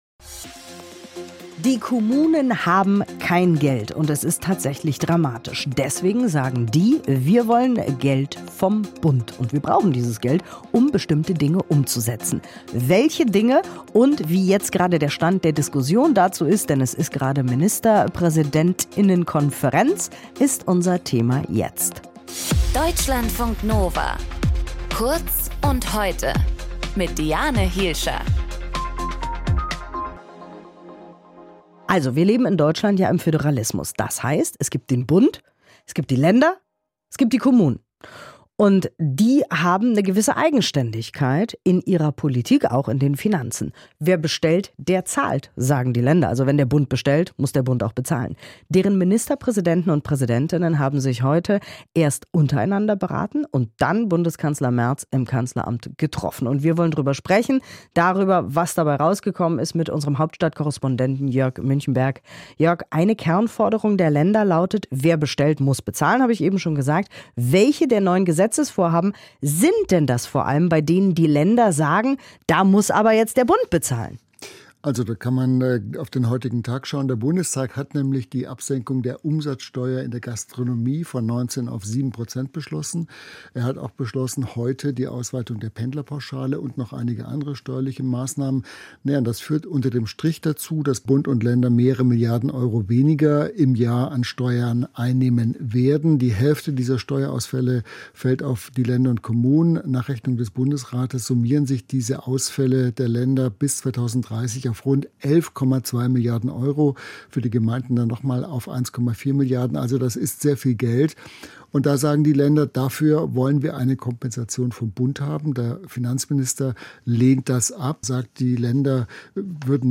Moderation:
Gesprächspartner: